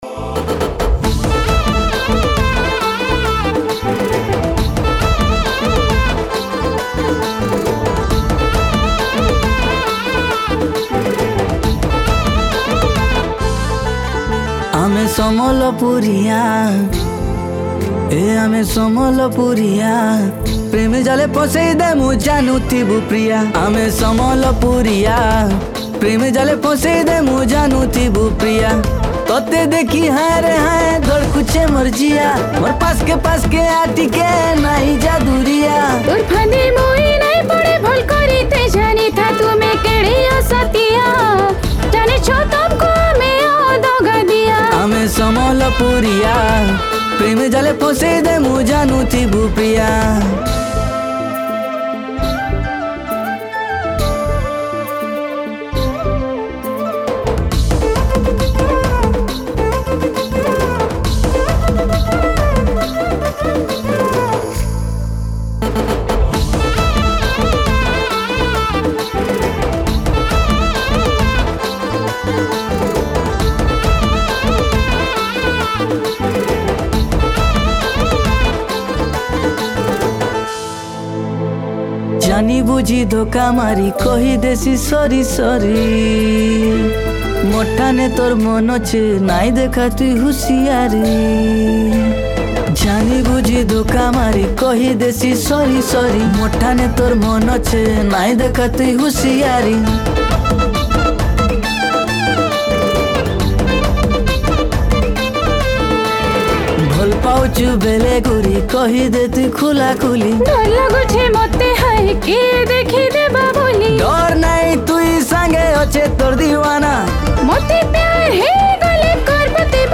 New Sambalpuri Song 2025